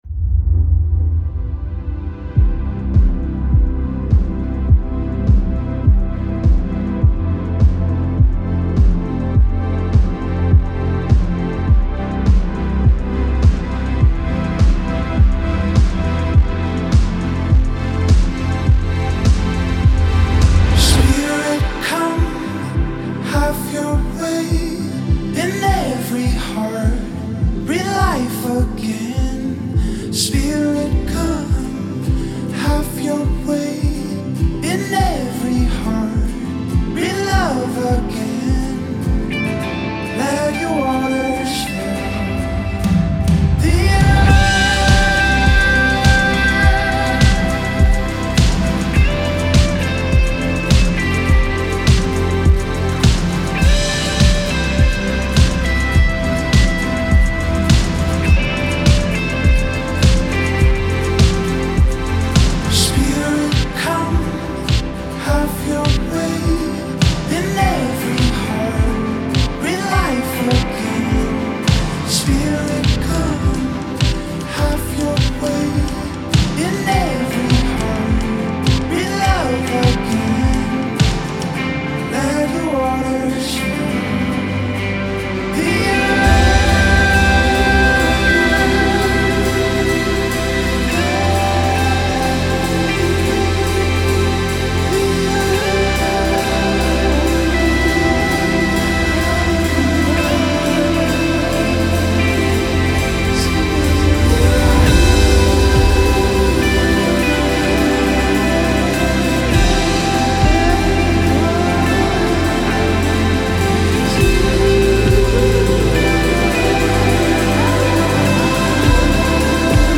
212 просмотров 28 прослушиваний 3 скачивания BPM: 103